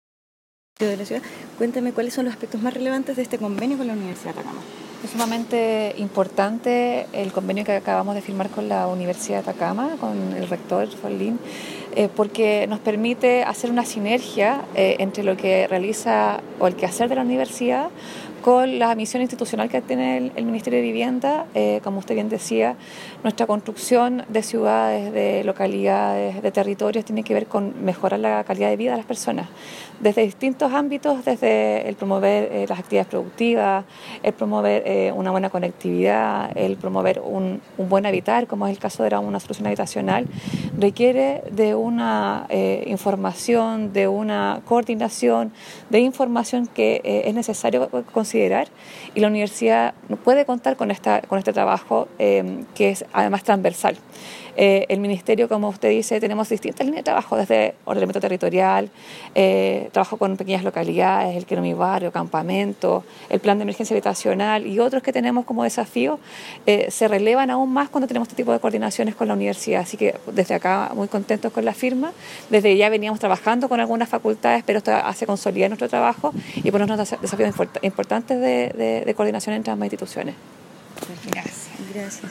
Cuña_Seremi_Minvu_Rocío_Díaz.mp3